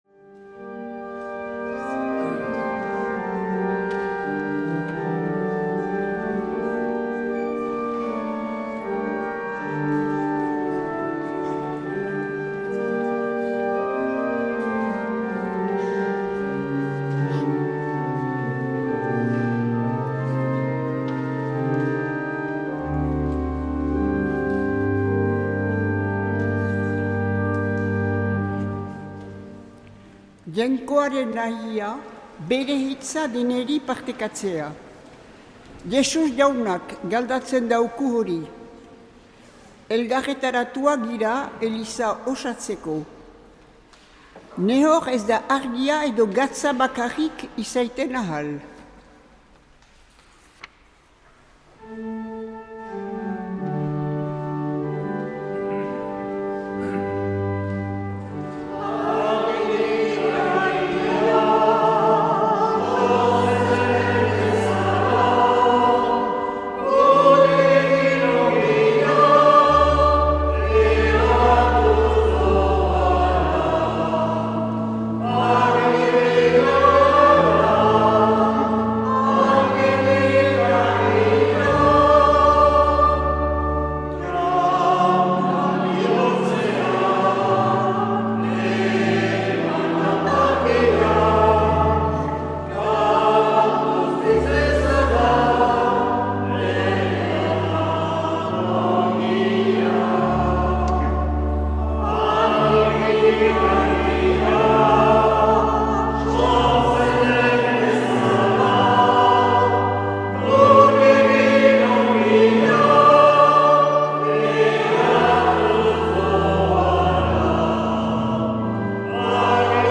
2023-02-05 Urteko 5. Igandea A - Uztaritze